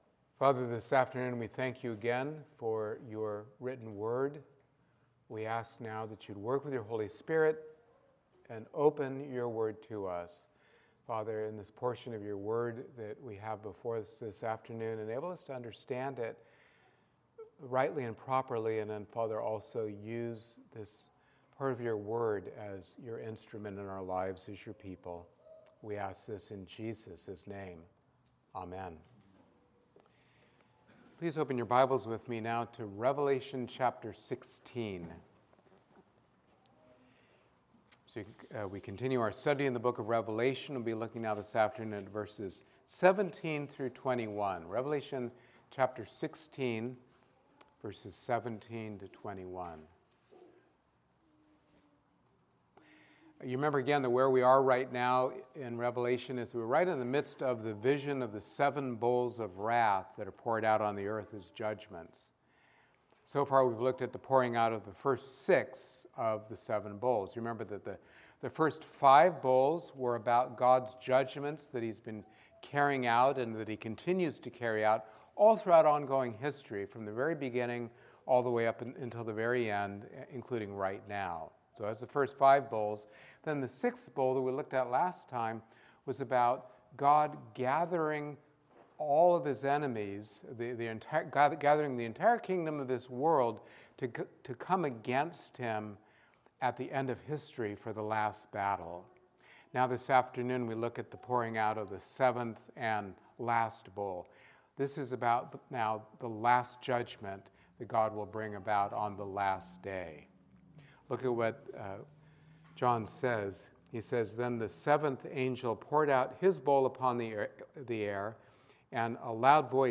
CPCMB Sample Sermons Revelation 16:17-21 Sep 21 2024 | 00:46:38 1x 00:00 / 36:28 Subscribe Share Revelation 16:17-21 Sep 21 2024 • 00:46:38 "The Seventh Bowl," 09/08/2024...